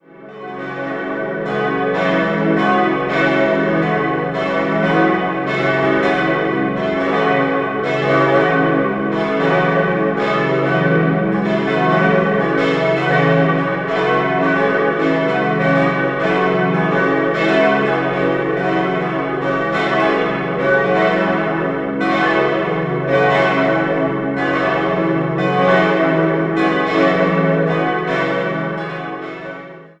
Das Gotteshaus wurde im Jahr 1950 nach Plänen des Augsburger Architekten Michael Kurz errichtet. 4-stimmiges Geläut: cis'-e'-fis'-gis' Alle Glocken wurden im Jahr 1953 vom Bochumer Verein für Gussstahlfabrikation in V7-Rippe gegossen.